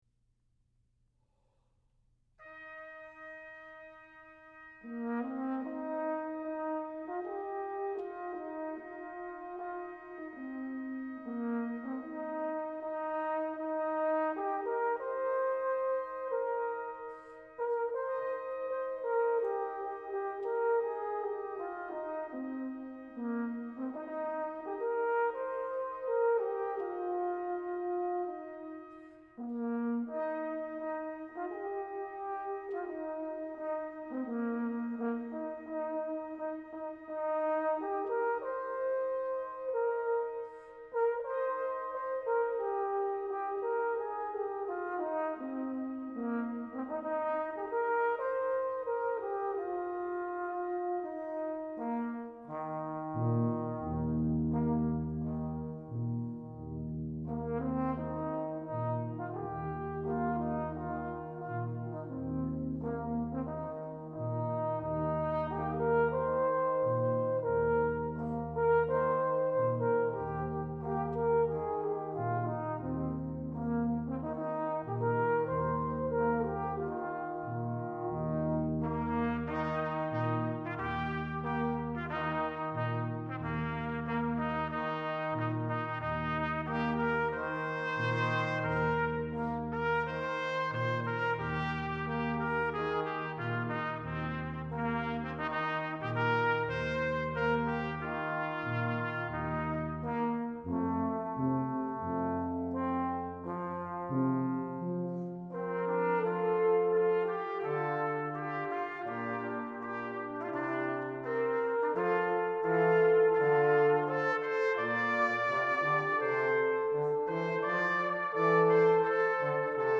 For Brass Quintet, Composed by Traditional.